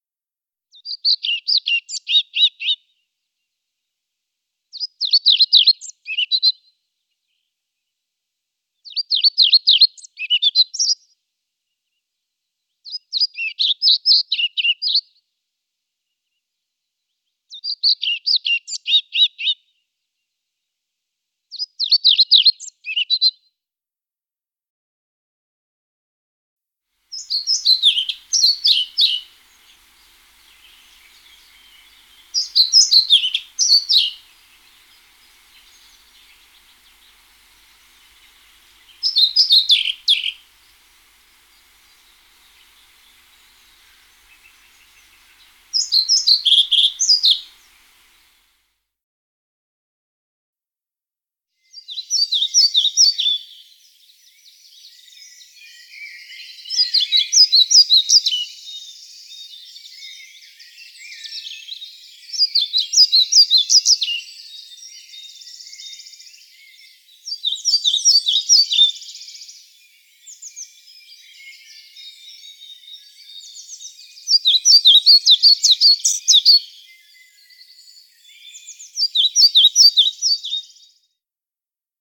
Trauerschnäpper – Kifö Sinzing
06-Trauerschnaepper-G.mp3